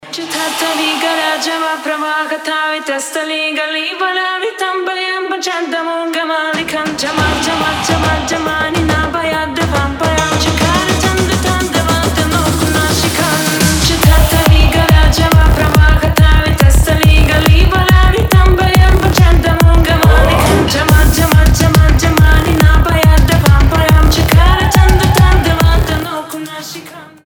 женский вокал
восточные мотивы
dance
EDM
electro house
индийские мотивы
Классный electro house с текстом на хинди.